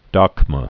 (däkmə)